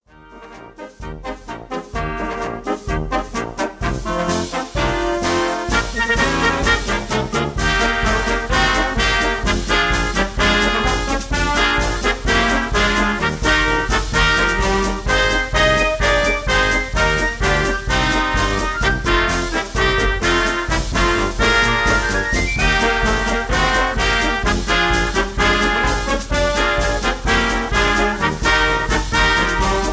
(Key-Bb)
Tags: backing tracks , irish songs , karaoke , sound tracks